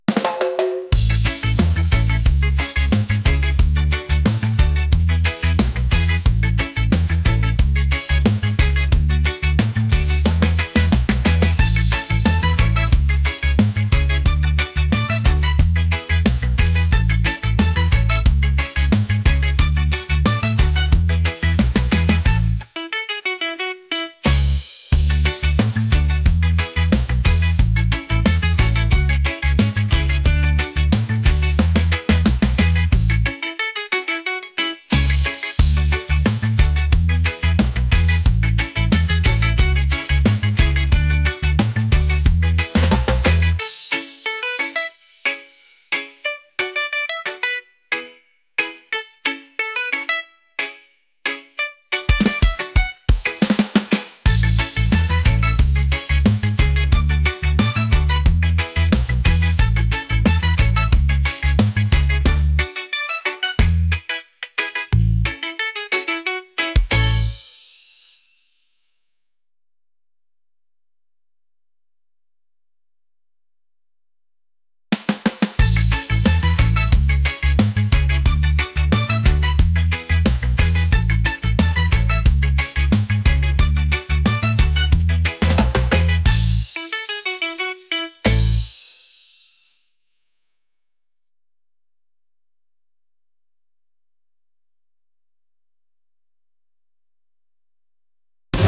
reggae | catchy | upbeat